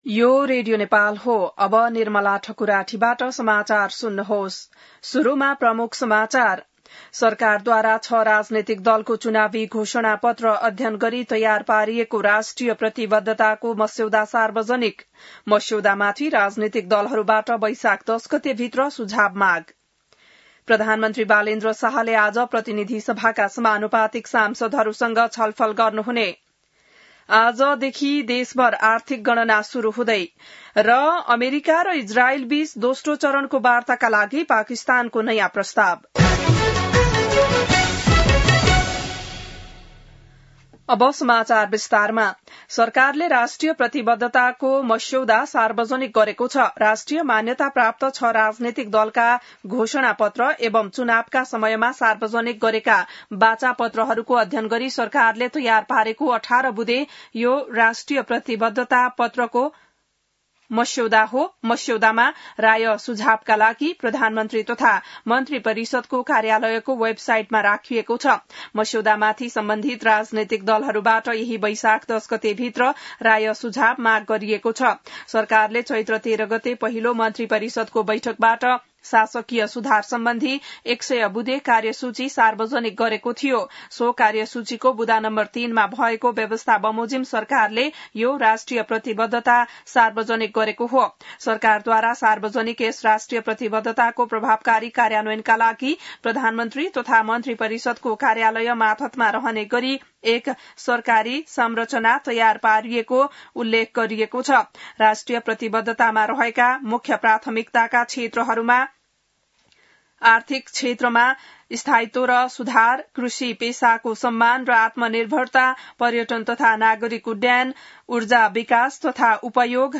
बिहान ९ बजेको नेपाली समाचार : २ वैशाख , २०८३